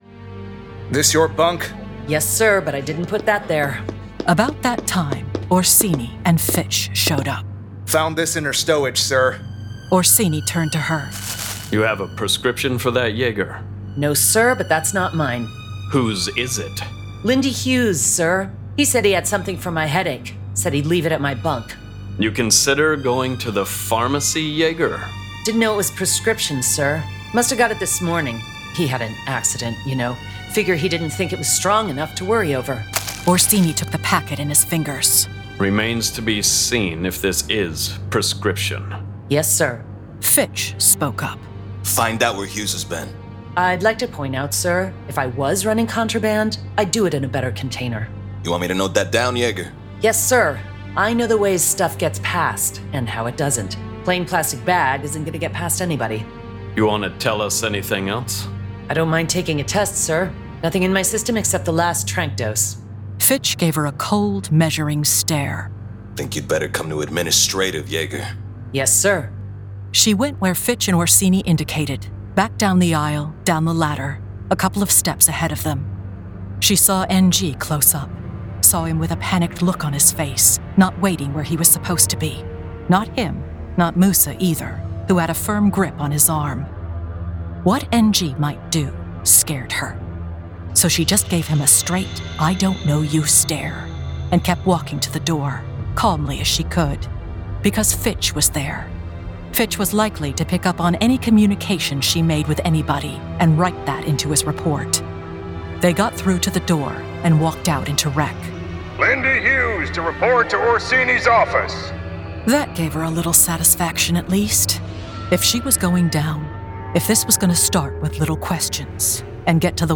Full Cast. Cinematic Music. Sound Effects.
[Dramatized Adaptation]
The classic sci-fi series, brought to life with a full cast, sound effects and cinematic music!